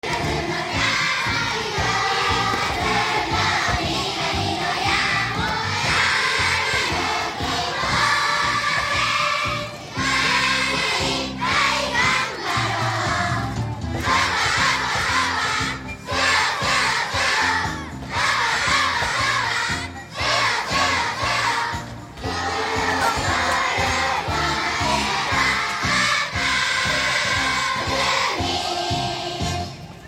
運動会 プレイバックPart１
開会式。 🎵『ゴーゴーゴー(運動会の歌)』 ★★元気な歌声はこちらをクリック★★運動会『ゴーゴーゴー(運動会の歌)』